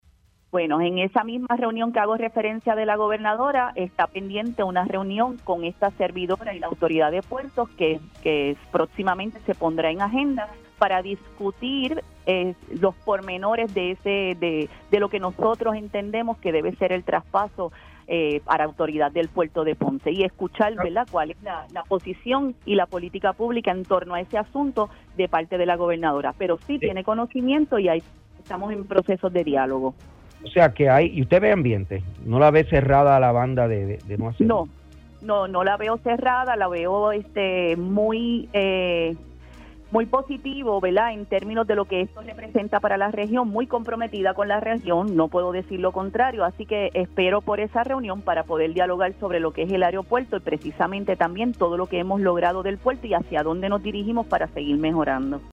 La alcaldesa de Ponce, Marlese Sifre, aseguró en Tiempo Igual que aún queda pendiente entre ella y la gobernadora Jenniffer González Colón una conversación respecto al traspaso del aeropuerto internacional Mercedita al municipio.